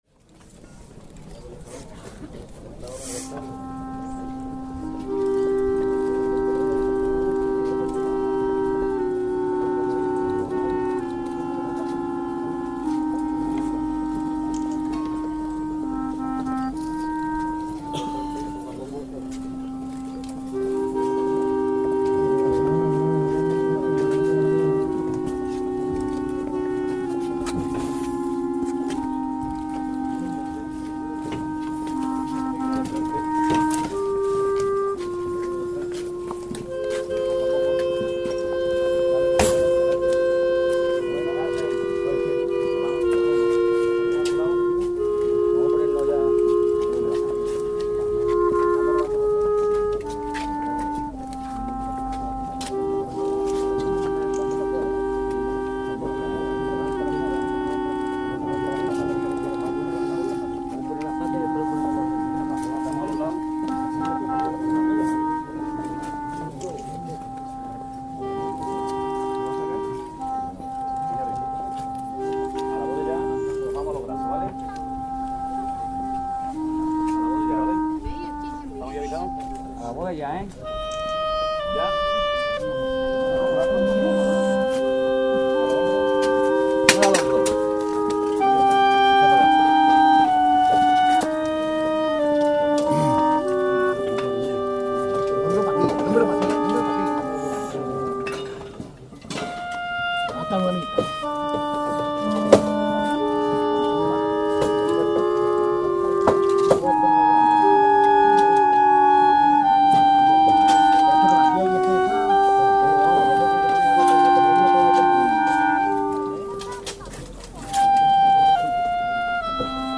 Capilla Musical para Trío de Instrumentos de Viento,